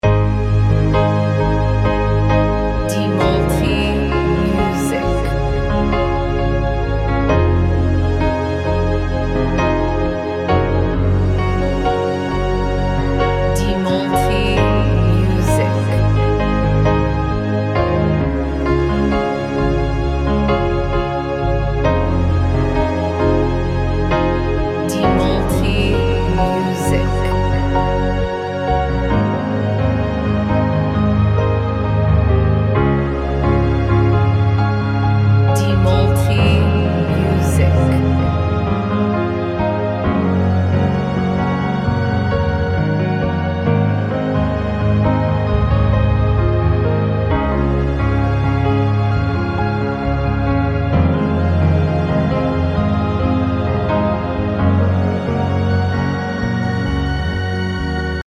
Modern Piano Instrumental
Lagu ini menangkap rasa sunyi yang ternyata penuh gema.